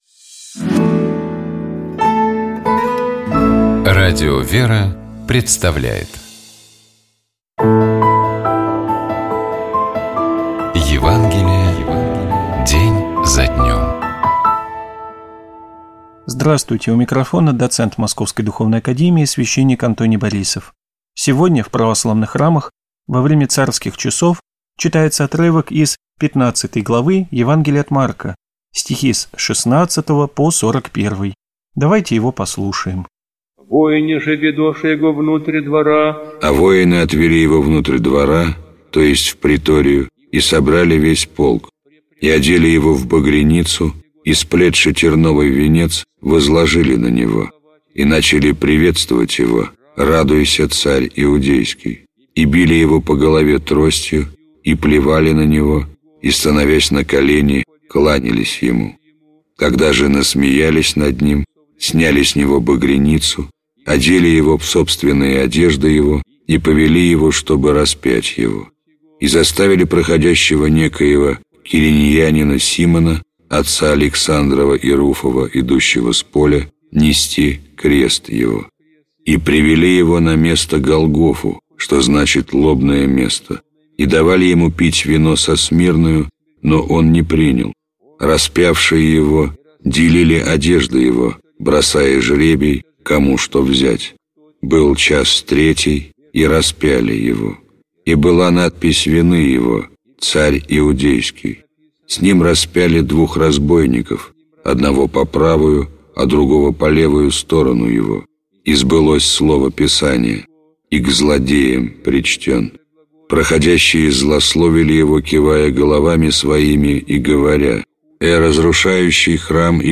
Читает и комментирует священник